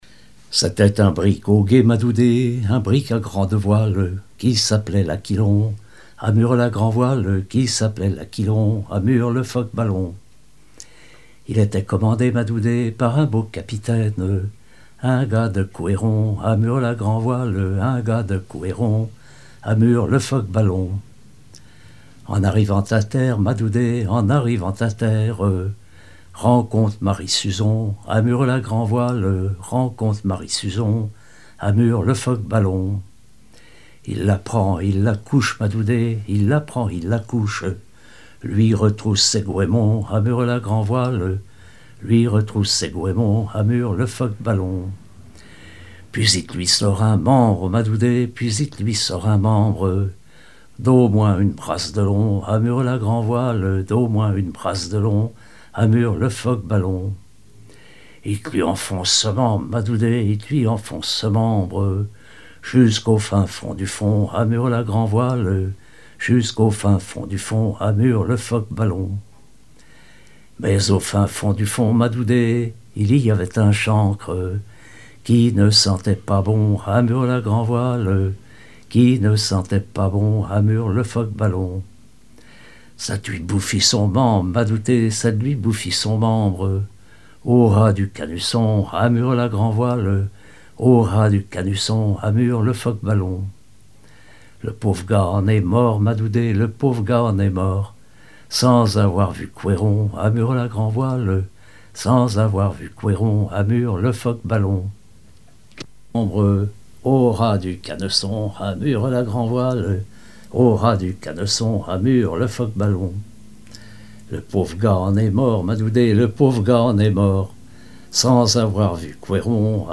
Saint-Gilles-Croix-de-Vie
chansons maritimes et paillardes
Pièce musicale inédite